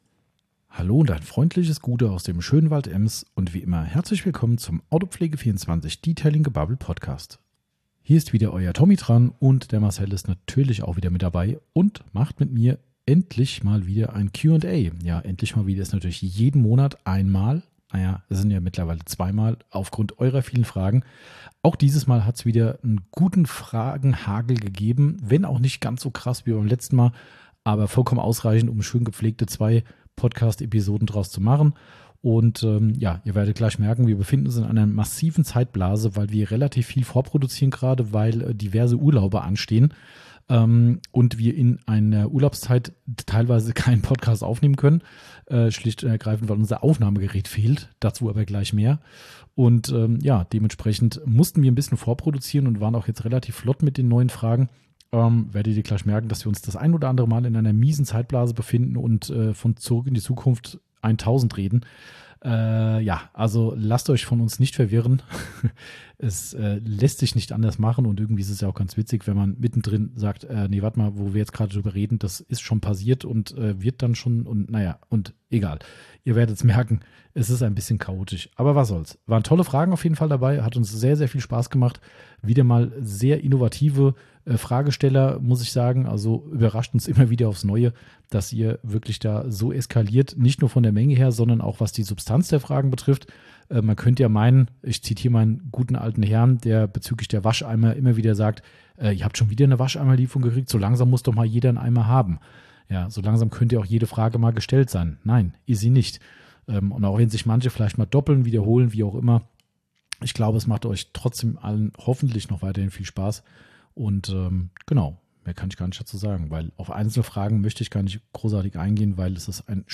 ;-) Somit ist es, dem Anlass entsprechend, 99 % Offtopic und ein Gebabbel mit hohem Lach-Faktor. Dabei geht es um viele Themen rund um Weihnachten, vom perfekten Weihnachtsessen bis zum Schmücken des Weihnachtsbaums!